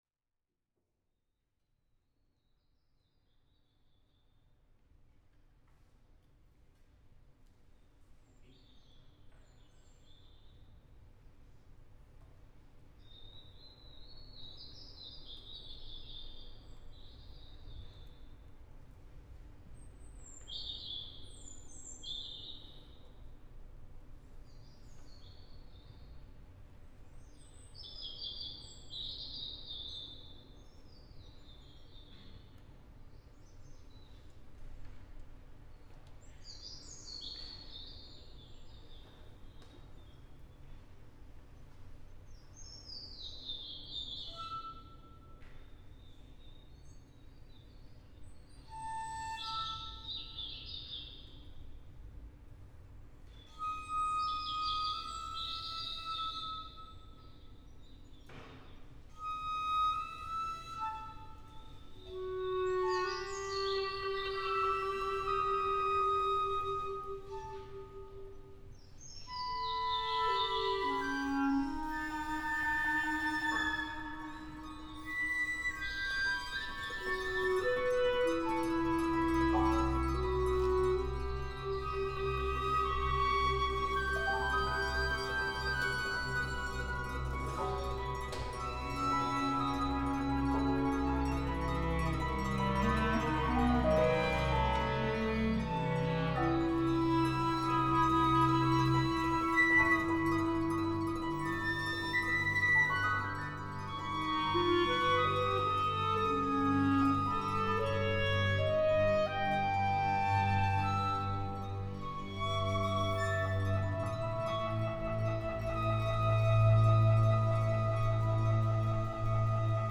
for orchestra and live electronics
at St John's Smith Square in London